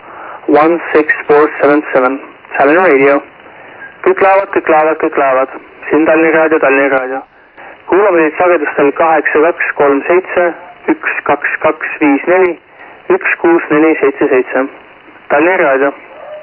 loud and clear - a a call in estonian language (by you-know-who-you-are, 2015)